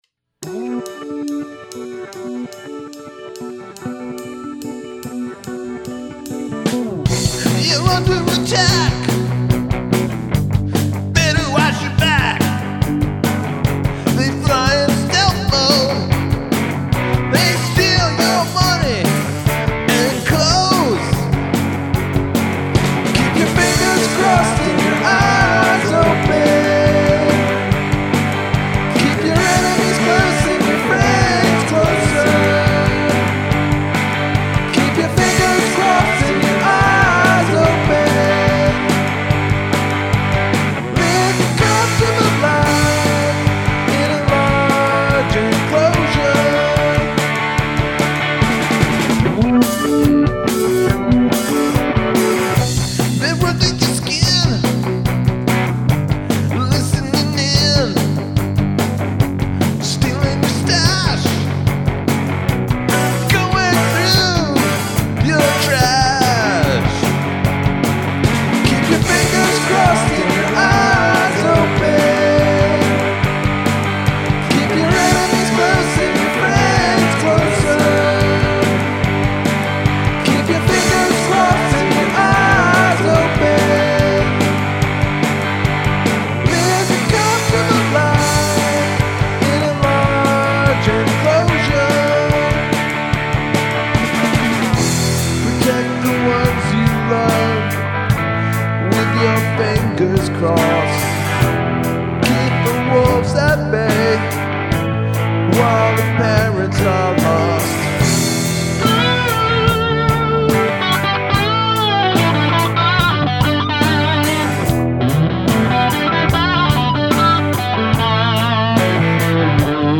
include a section of backwards singing or instrumentation